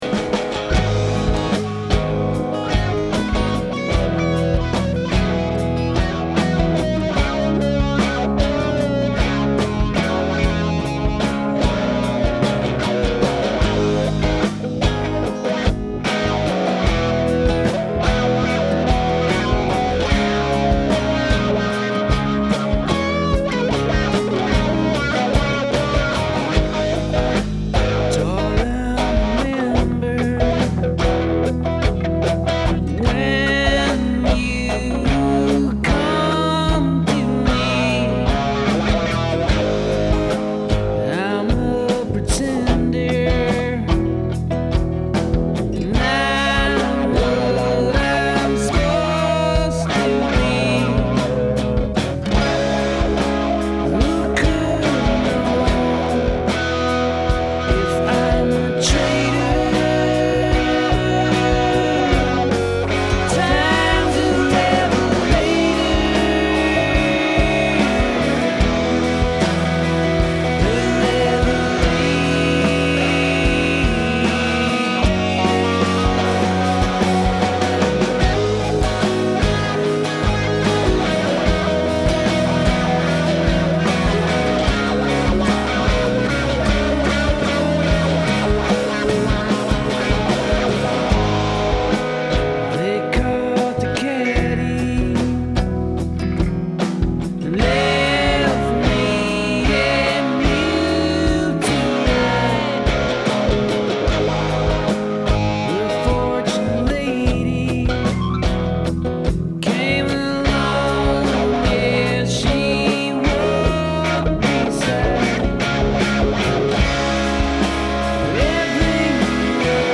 lost sessions